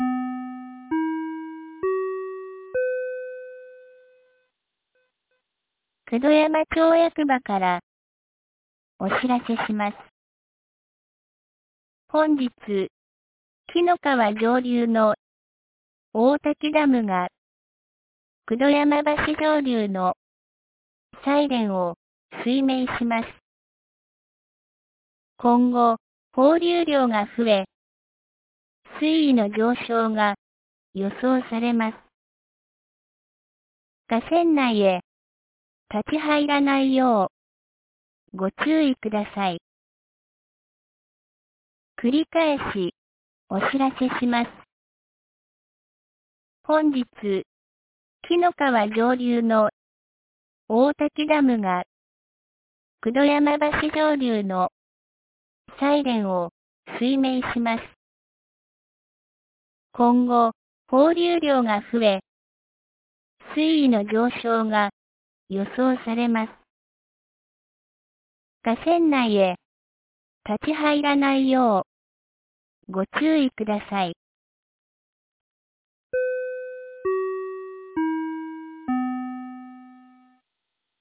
2023年08月14日 18時31分に、九度山町より紀ノ川沿線へ放送がありました。